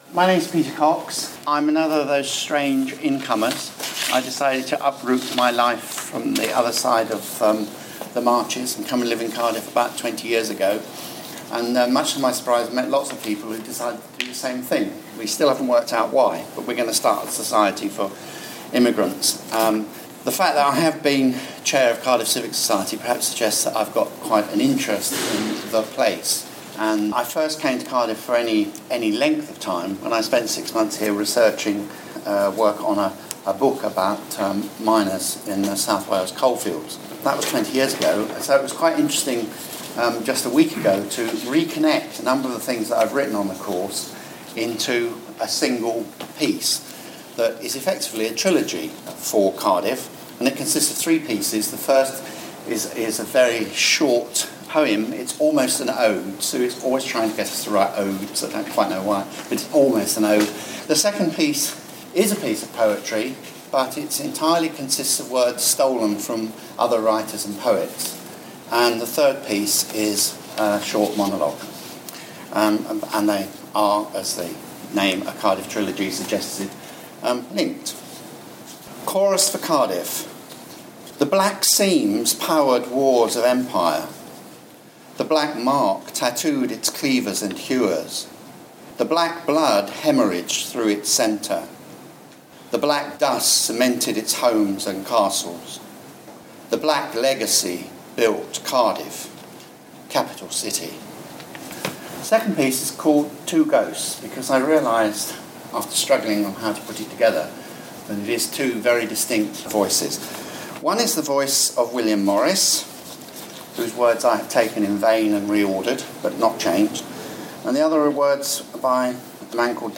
An ode, a poem and a monologue about miners and Cardiff given at "The Cardiff Story", the Old Library, Cardiff